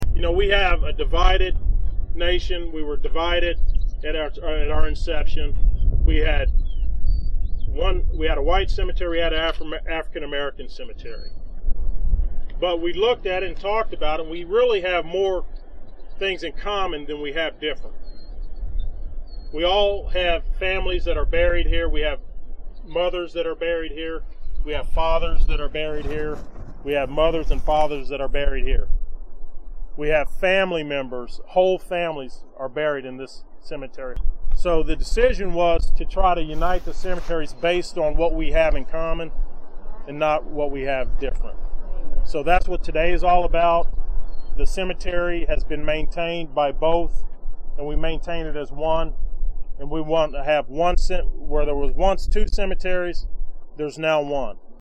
cambridge-cemetery-wreath-laying-ceremony-5-28-22
A wreath laying ceremony was held Saturday, May 28, to commemorate the restoration and desegregation of Cambridge Cemetery just north and east of Gilliam in Saline County.